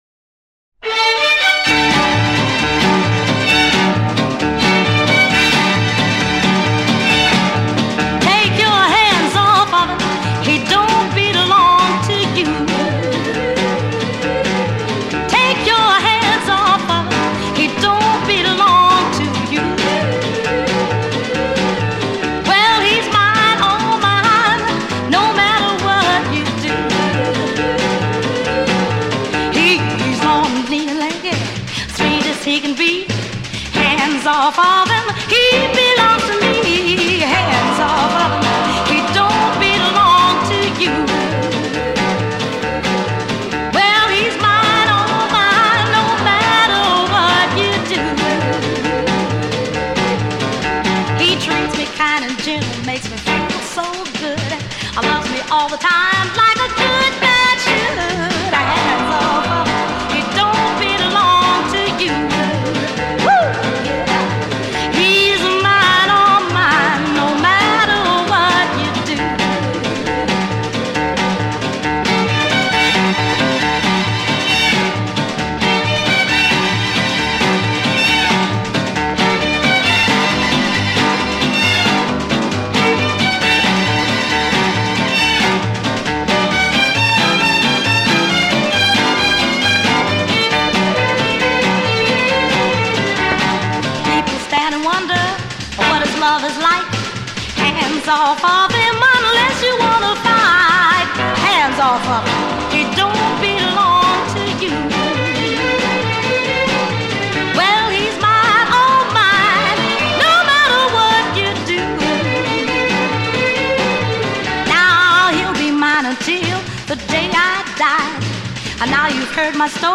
Genre: Pop
Style: Vocal